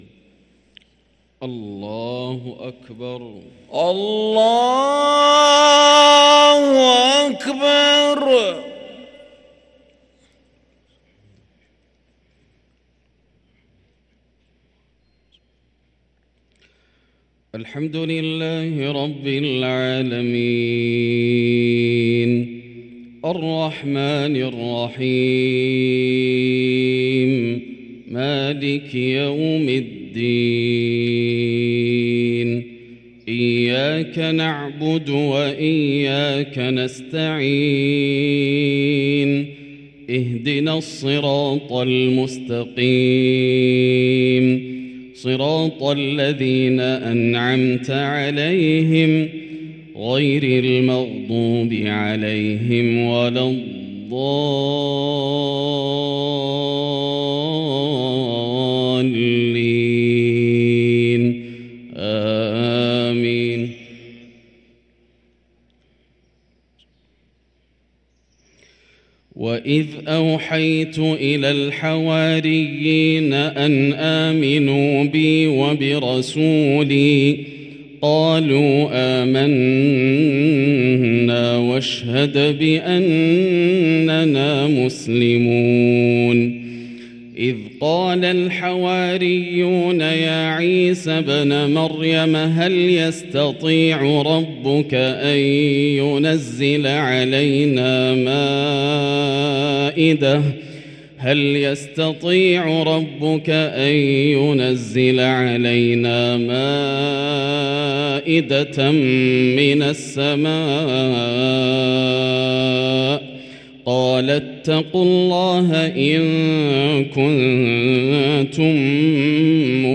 صلاة العشاء للقارئ ياسر الدوسري 6 شعبان 1444 هـ
تِلَاوَات الْحَرَمَيْن .